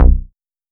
8 BALL BAS-R.wav